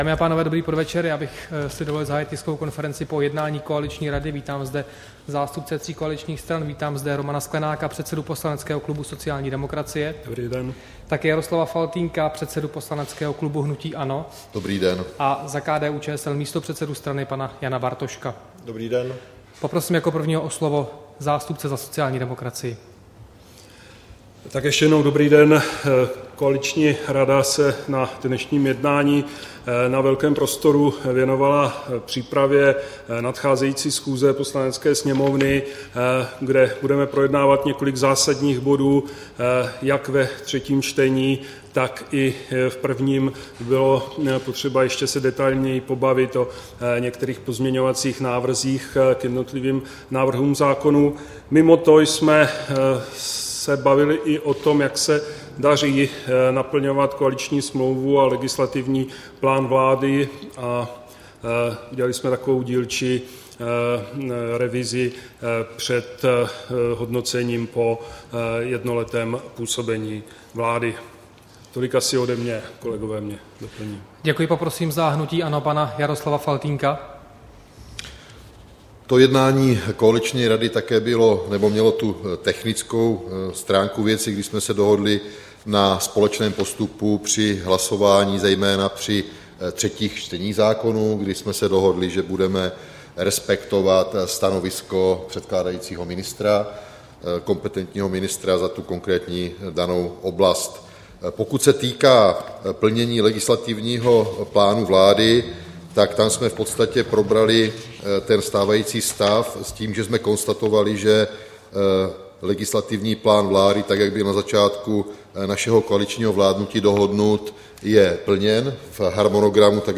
Tisková konference po jednání koaliční rady, 22. září 2014